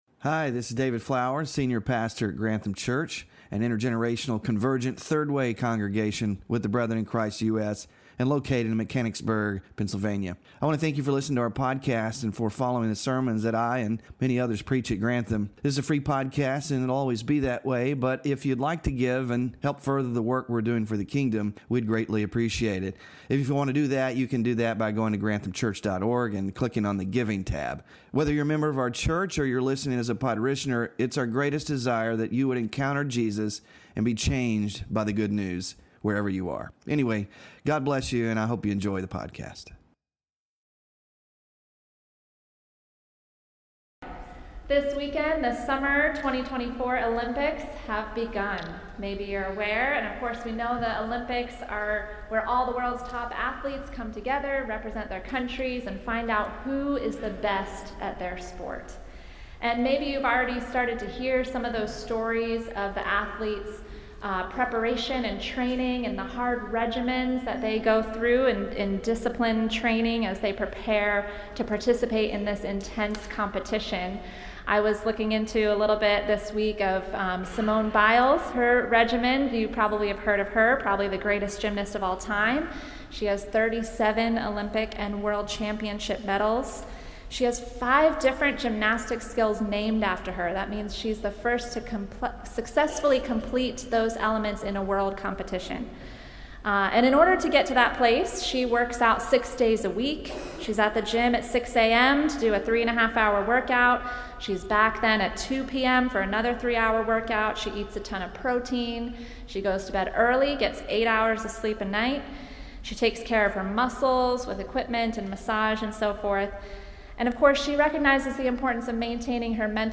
FRUIT OF THE SPIRIT WK9-SELF-CONTROL SERMON SLIDES